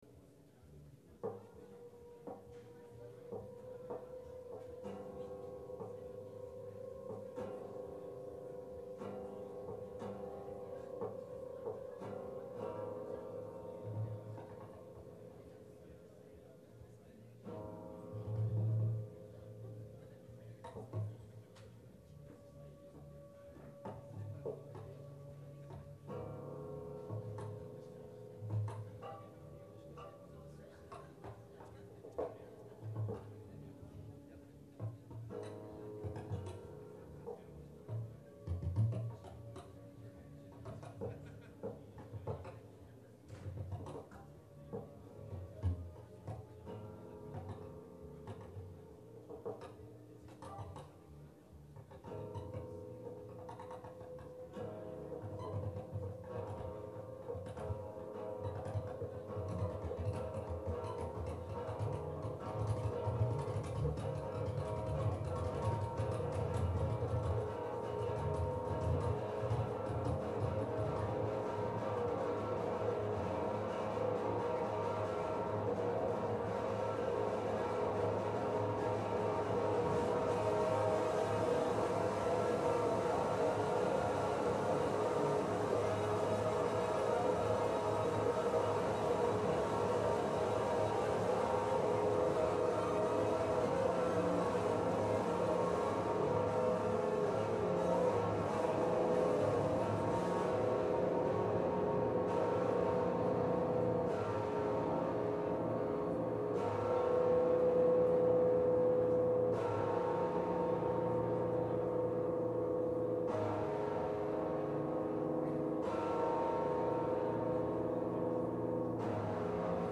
Concert au Jardin Moderne - Table d'hotes - 20 02 03